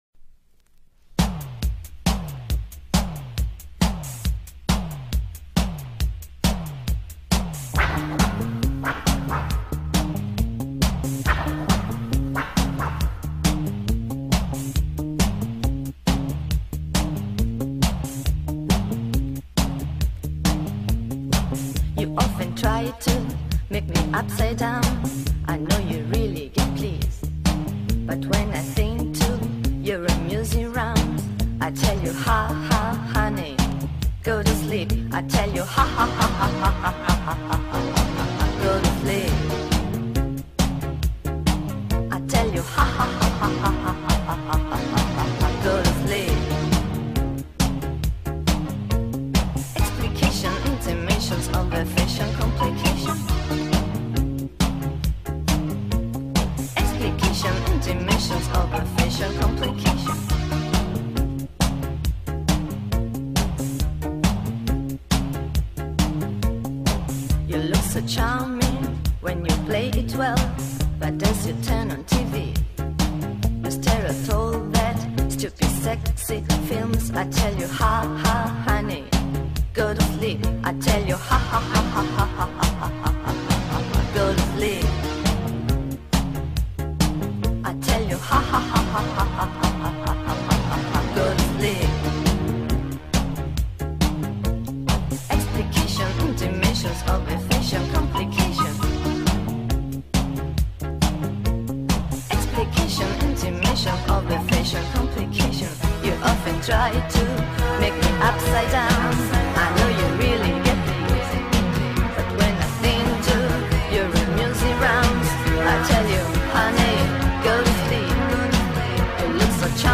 From the peak era of italo-disco.